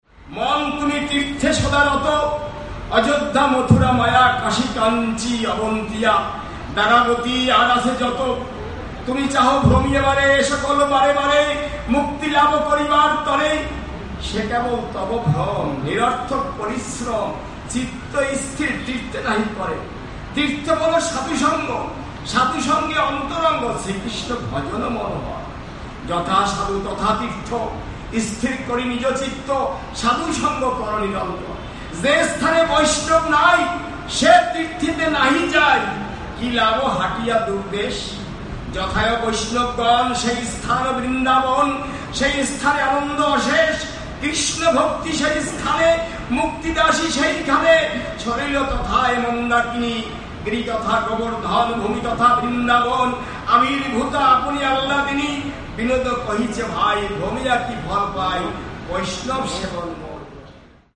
Recited at Srila Bhaktivinod Thakur's appearance day festival at Sri Surabhi Kunja: